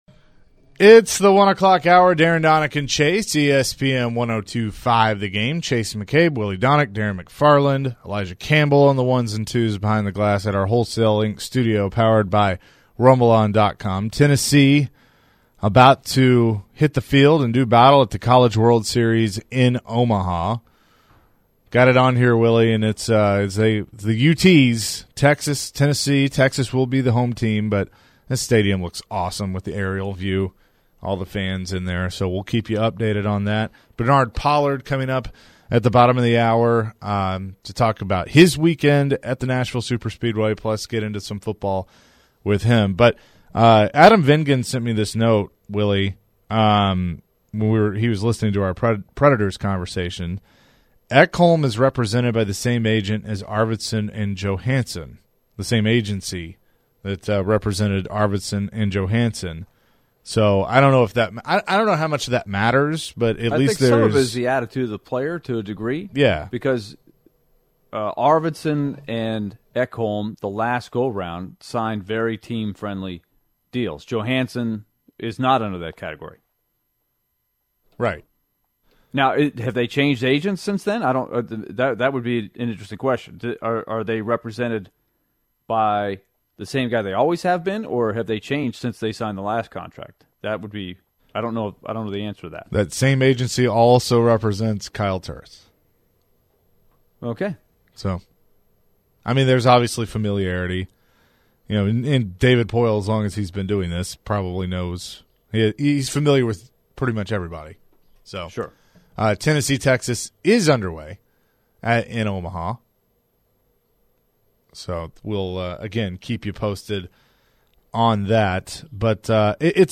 In the final hour of Tuesday's show: the guys chat with former Titans safety Bernard Pollard about his experience at the Ally 400, the guys discuss what the Preds should do this off-season and more!